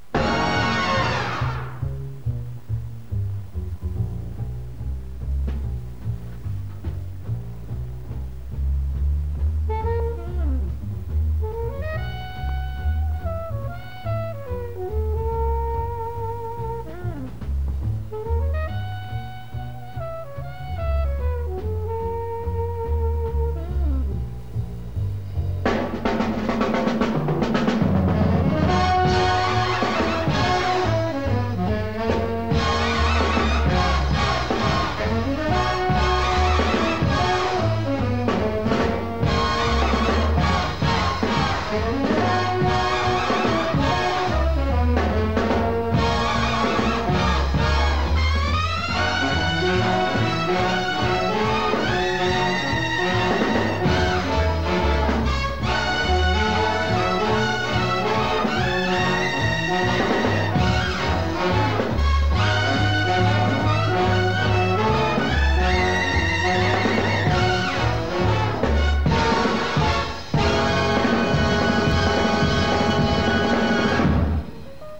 Musica
Track Music